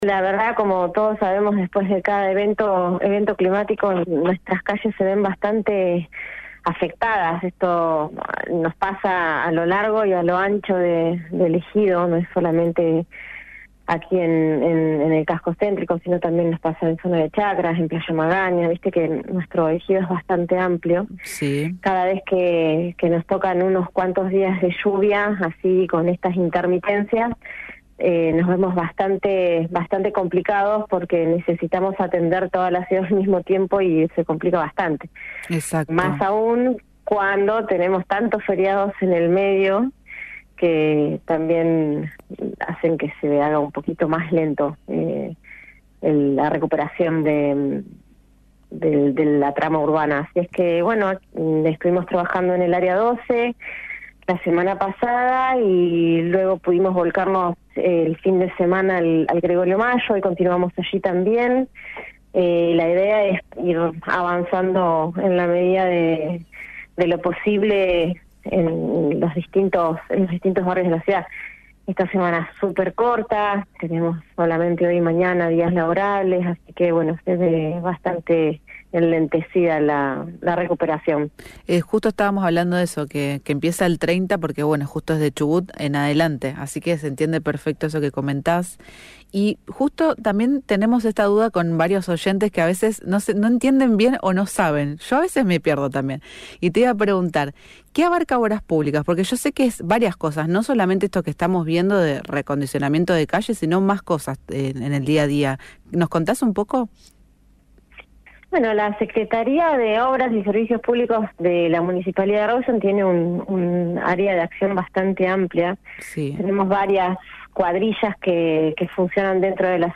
Afirma la Lic. Mercedes Bagalciaga, secretaria de Obras y servicios públicos de Rawson. Si querés escuchar la entrevista completa donde se refirió a qué sucede con las obras cuando hay lluvias intensas o feriados extra largos, escucha el siguiente audio: